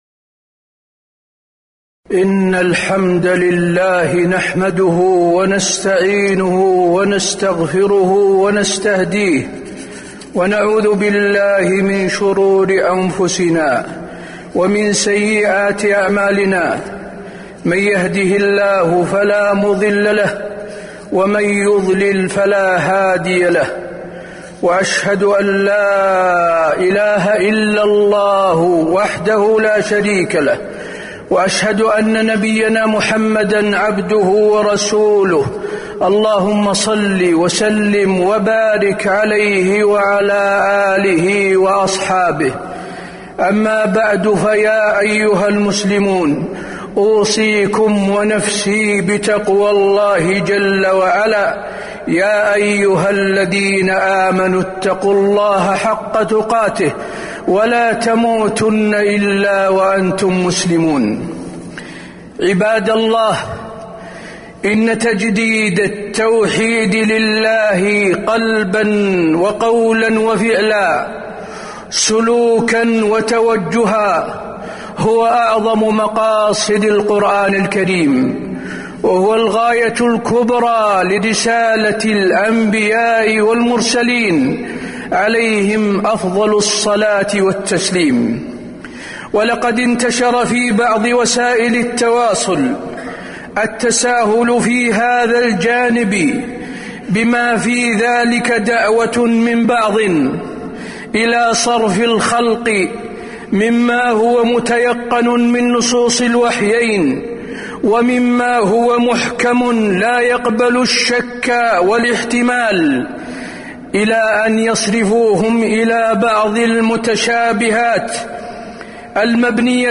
تاريخ النشر ١٥ جمادى الأولى ١٤٤٤ هـ المكان: المسجد النبوي الشيخ: فضيلة الشيخ د. حسين بن عبدالعزيز آل الشيخ فضيلة الشيخ د. حسين بن عبدالعزيز آل الشيخ التوحيد أهميته وفضائله